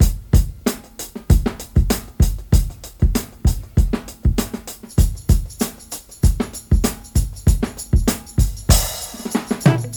• 96 Bpm HQ Breakbeat Sample F Key.wav
Free drum beat - kick tuned to the F note.
96-bpm-hq-breakbeat-sample-f-key-CEg.wav